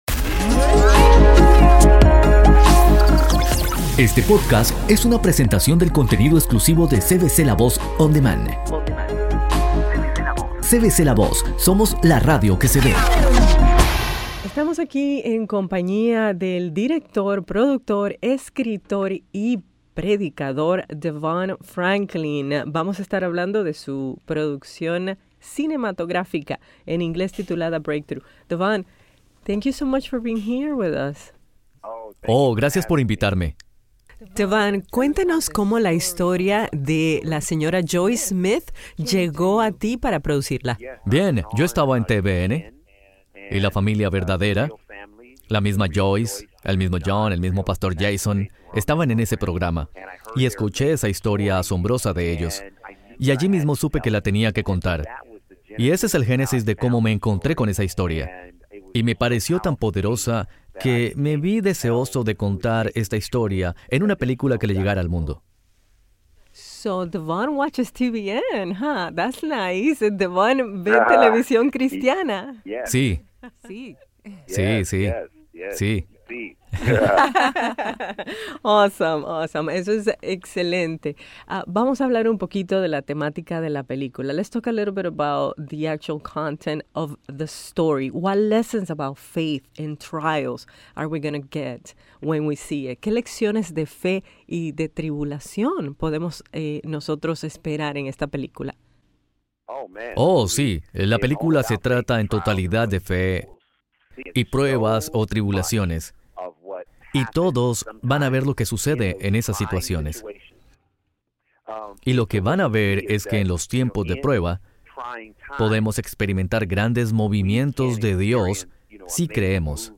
Entrevista a Devon Franklin, productor de la película ≪Breakthrough≫